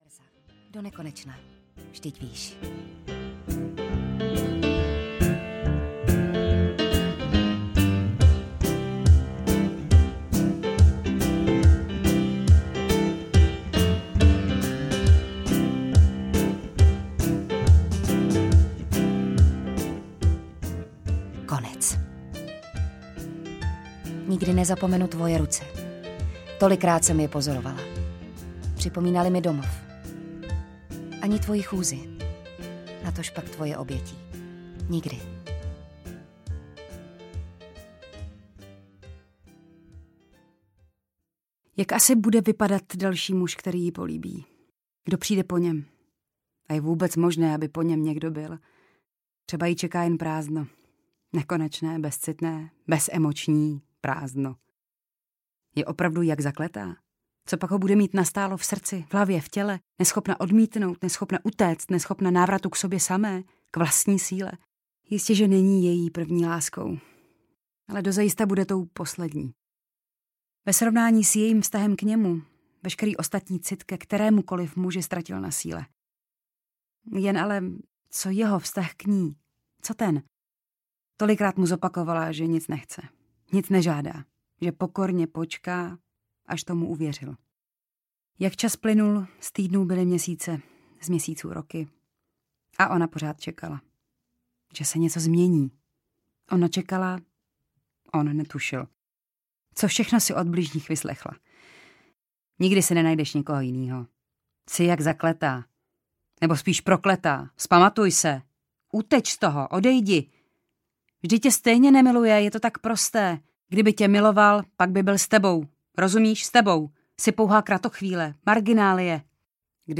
Momentky audiokniha
Ukázka z knihy
• InterpretJitka Čvančarová, Jitka Ježková